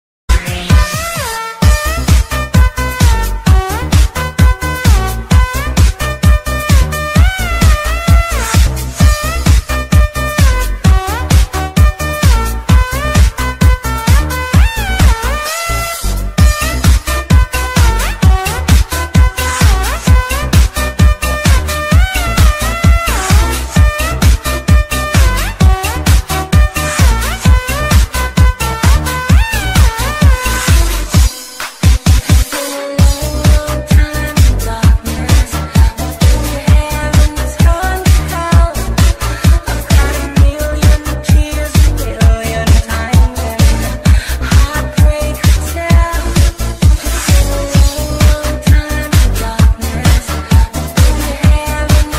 Nhạc Chuông DJ - Nonstop